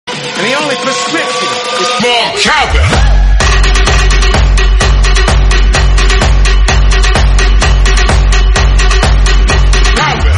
Play, download and share Morecowvell original sound button!!!!
more-cowbell-audiotrimmer.mp3